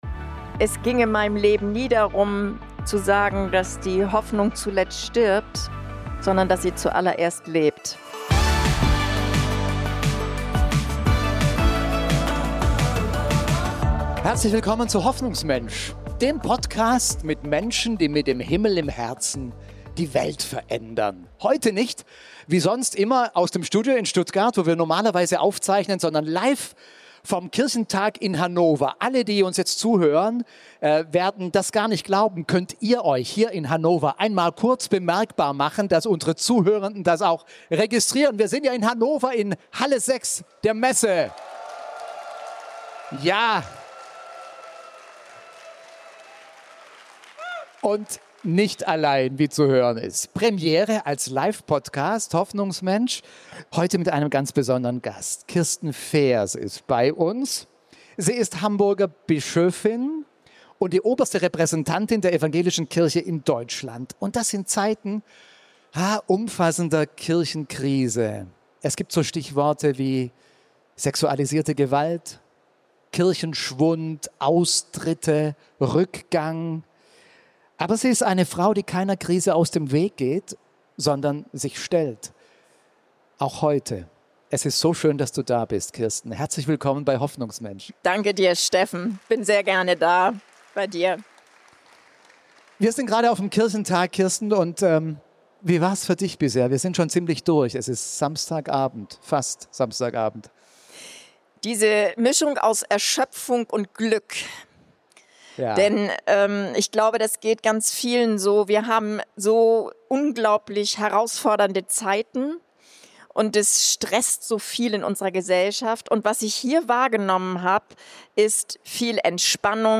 Die Folge wurde am 3. Mai vor Live-Publikum beim Deutschen Evangelischen Kirchentag in Hannover aufgezeichnet.